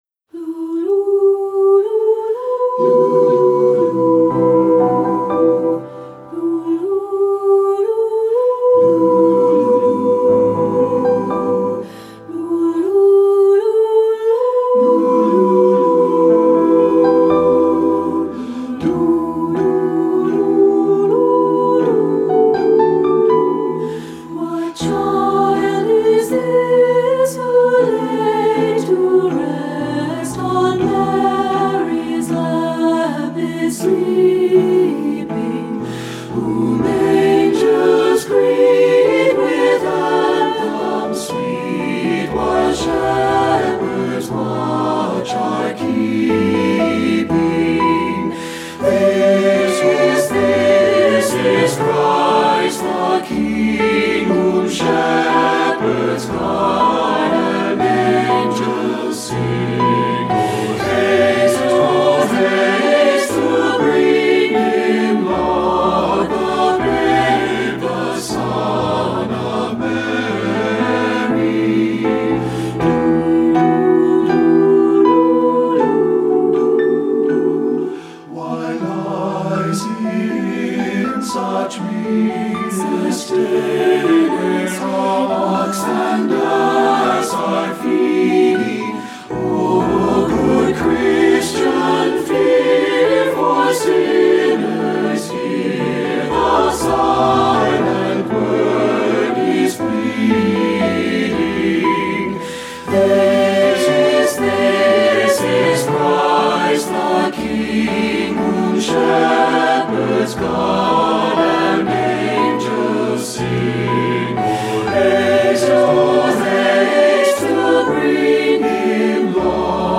Composer: 16th Century English Carol
Voicing: SATB and Piano